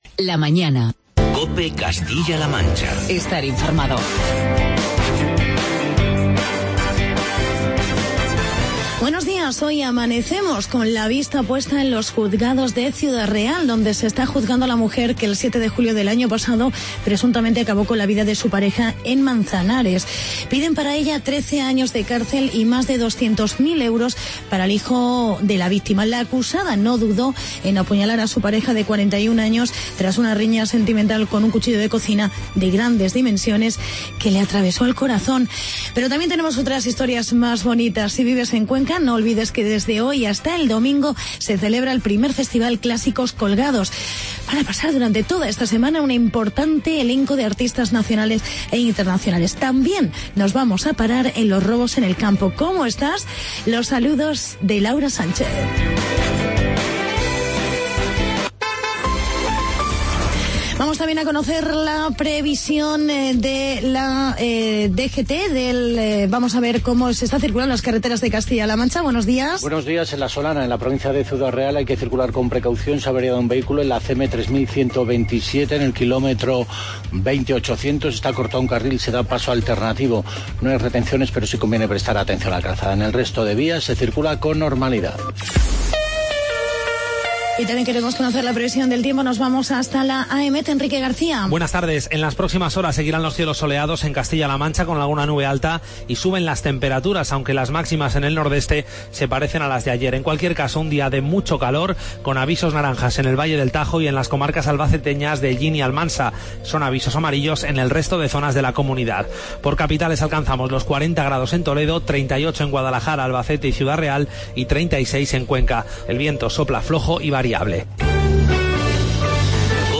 Noticias y entrevista con robos en el campo de Ciudad Real, el último de 1.500 kg de almendras.